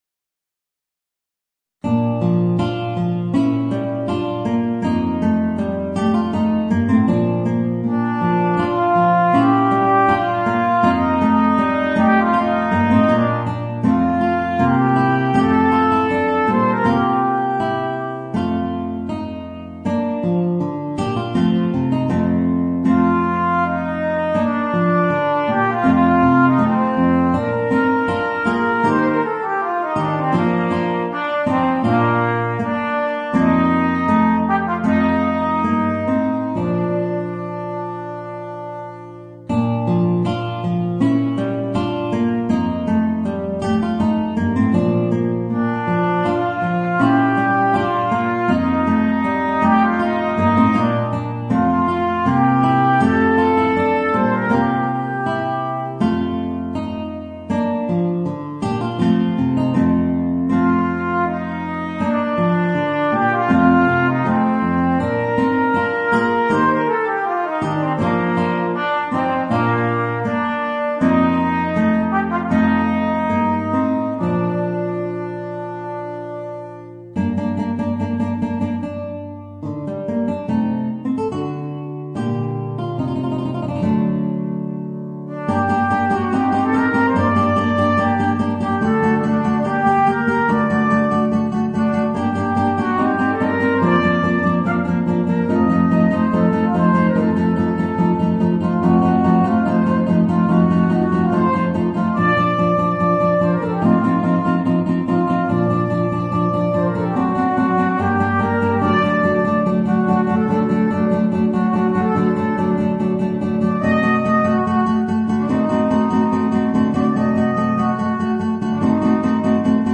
Gitarre & Trompete